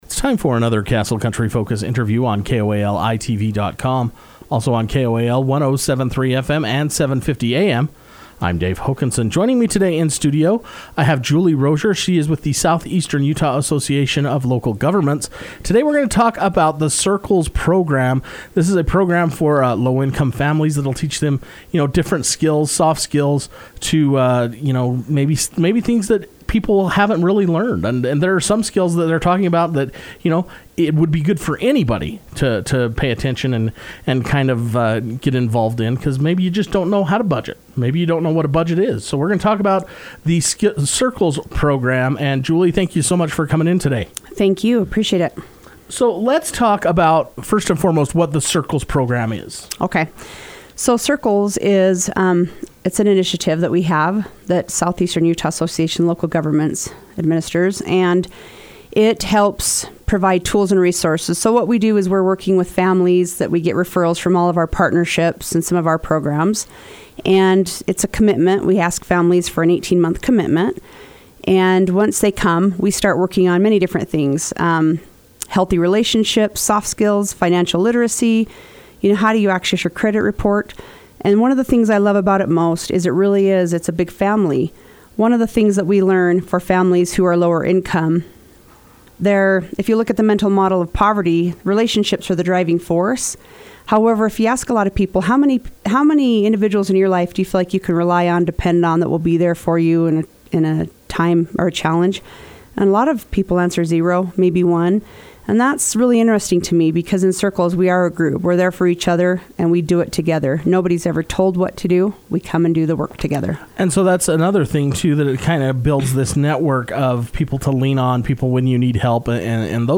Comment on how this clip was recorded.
took time to come into Castle Country Radio to talk about all the details of this program.